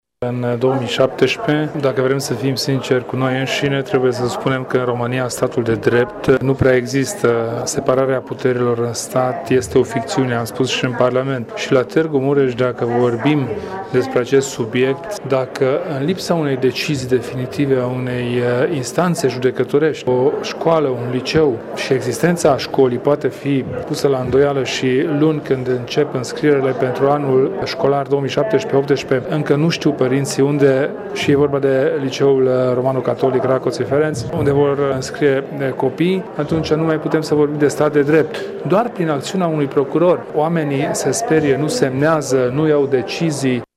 Preşedintele UDMR, Kelemen Hunor, a declarat azi la Tîrgu-Mureş, că în România statul de drept ‘nu prea există’, întrucât separarea puterilor în stat ar fi ‘o ficţiune’.